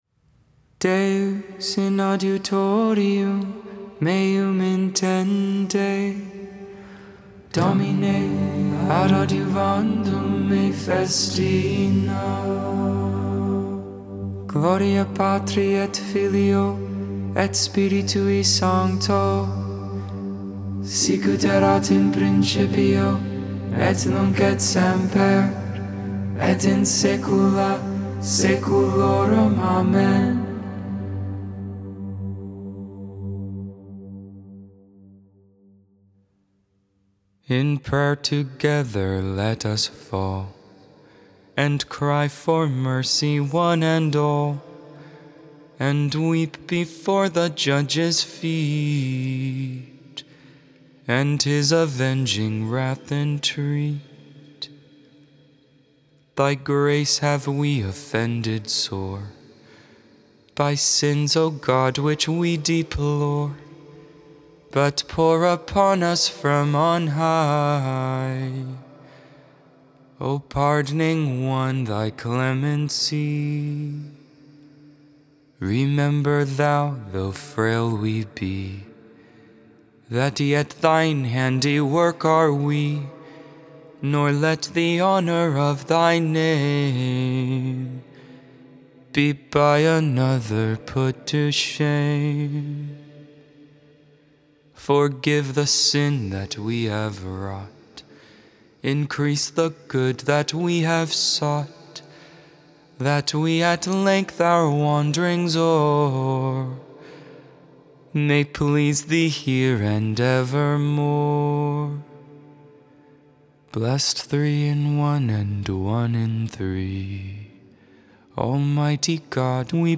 Benedictus (English, Tone 8, Luke 1v68-79) Intercessions The Lord's Prayer Concluding Prayers Hail Mary The Liturgy of th